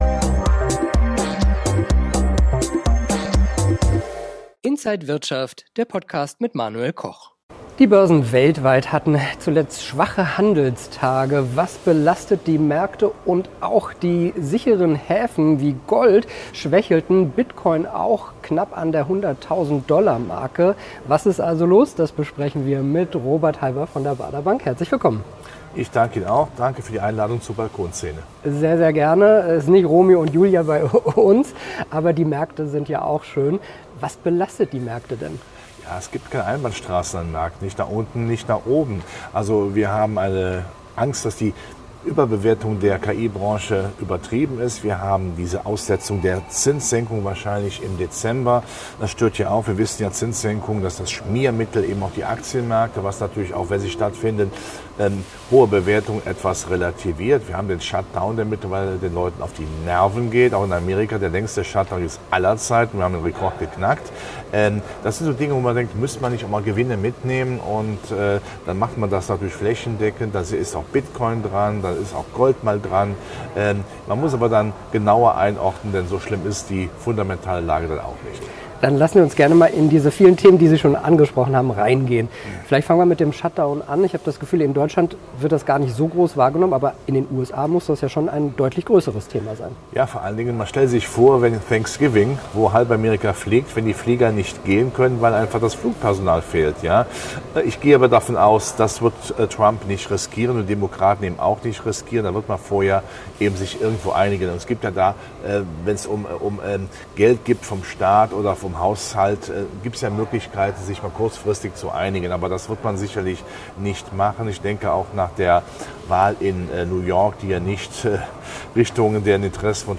Alle Details im Interview von Inside